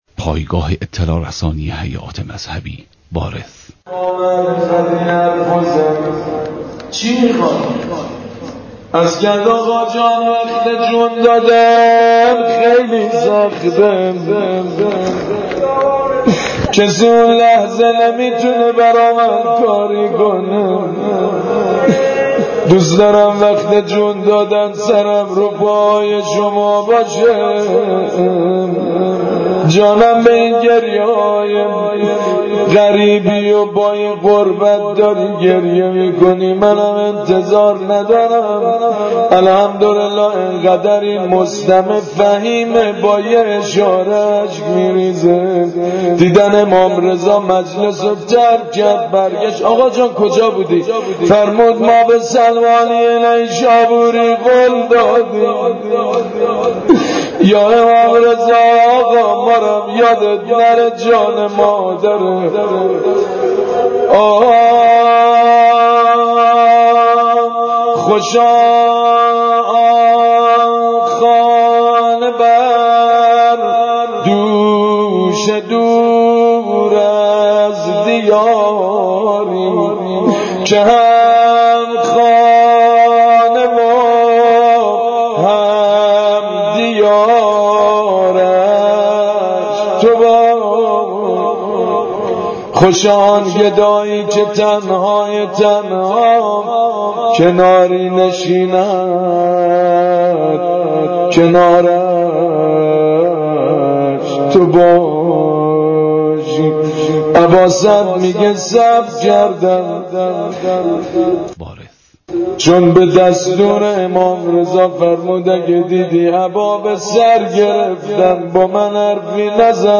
تجمع اعتراضی به فیلم رستاخیز در مسجد ارک تهران